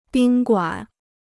宾馆 (bīn guǎn): guesthouse; lodge.